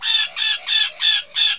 BIRD1.WAV
1 channel